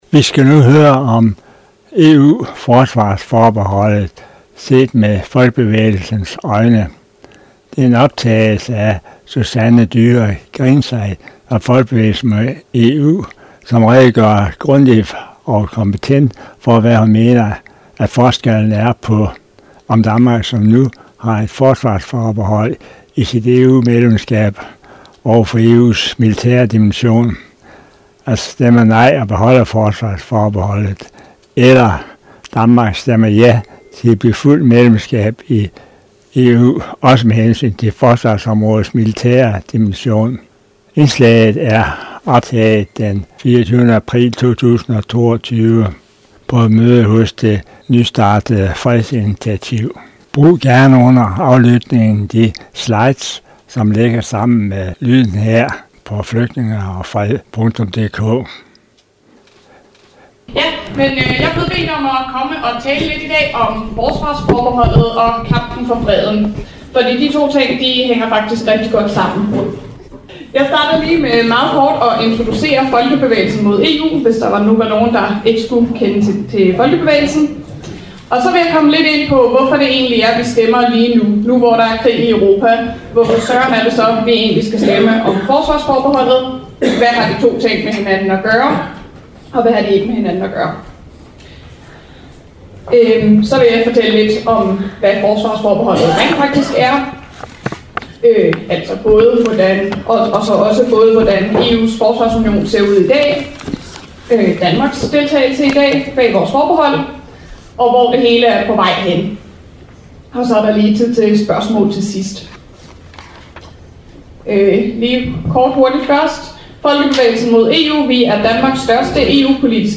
Foredraget blev holdt på Fredsinitiativets møde hos 3F d. 24. april 2022